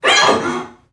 NPC_Creatures_Vocalisations_Robothead [3].wav